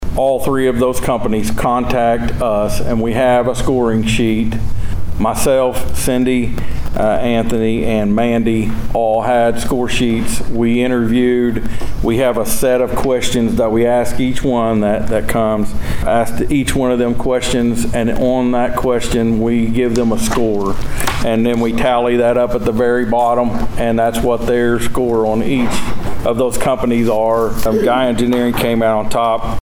District Two Commissioner Steve Talburt